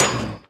1.21.5 / assets / minecraft / sounds / mob / blaze / hit1.ogg
hit1.ogg